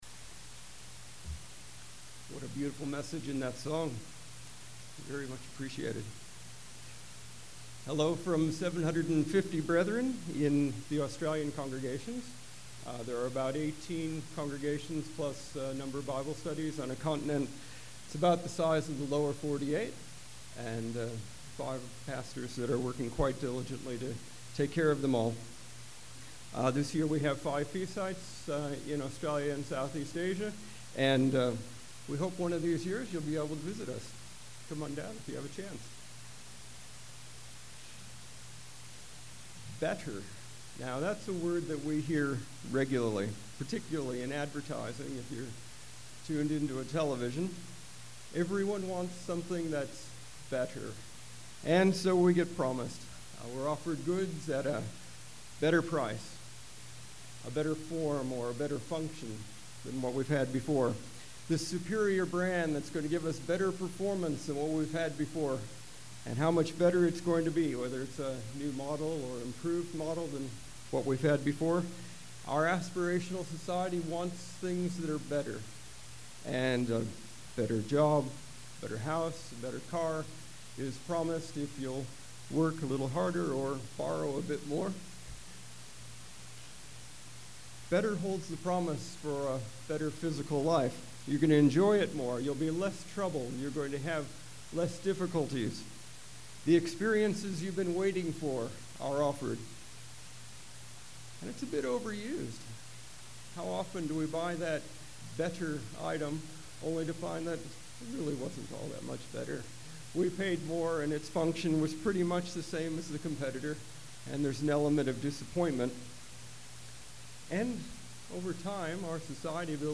This sermon was given at the Lake Texoma, Texas 2017 Feast site.